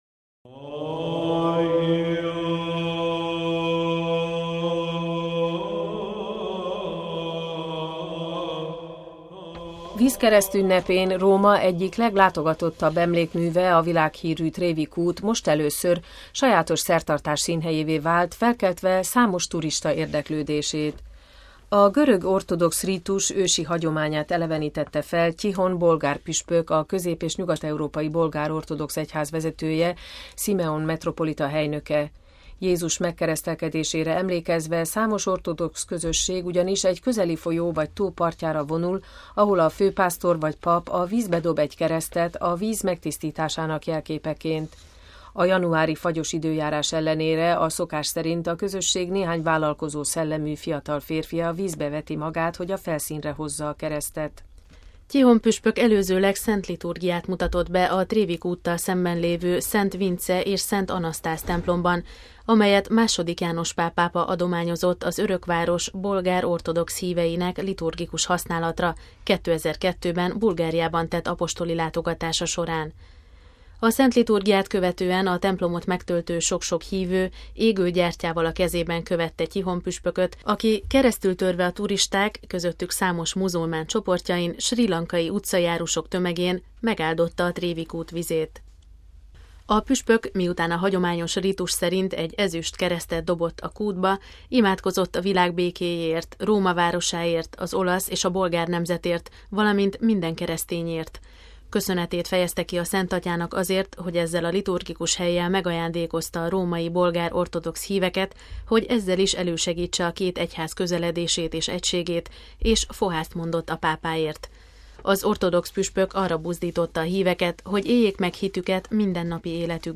Ortodox szertartás a Trevi-kútnál Urunk megkeresztelkedésének ünnepére emlékezve